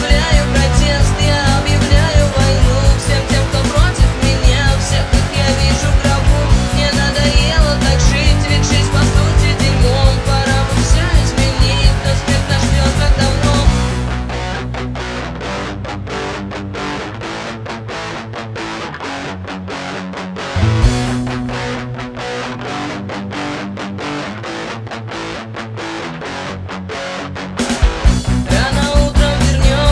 Жанр: Русские
# Русский рок